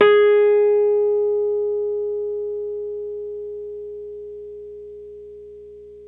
RHODES CL0DR.wav